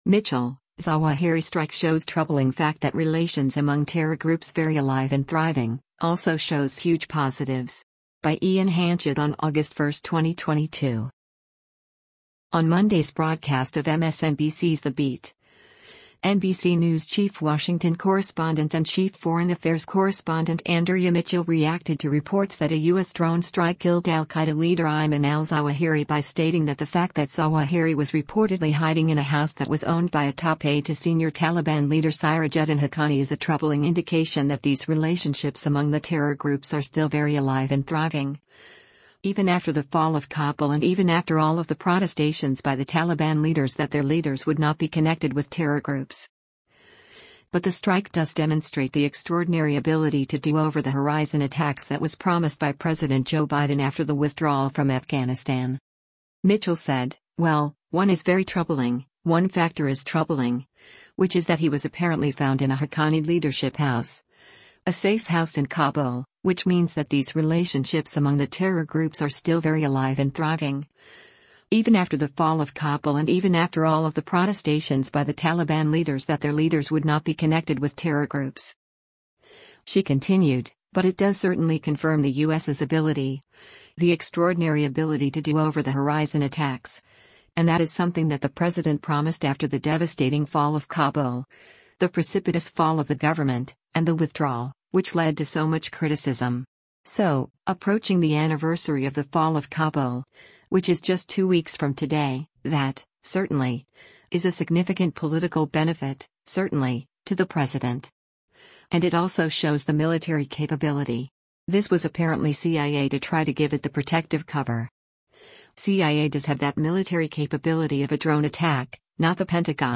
On Monday’s broadcast of MSNBC’s “The Beat,” NBC News Chief Washington Correspondent and Chief Foreign Affairs Correspondent Andrea Mitchell reacted to reports that a U.S. drone strike killed al-Qaeda leader Ayman al-Zawahiri by stating that the fact that Zawahiri was reportedly hiding in a house that was owned by a top aide to senior Taliban leader Sirajuddin Haqqani is a “troubling” indication that “these relationships among the terror groups are still very alive and thriving, even after the fall of Kabul and even after all of the protestations by the Taliban leaders that their leaders would not be connected with terror groups.”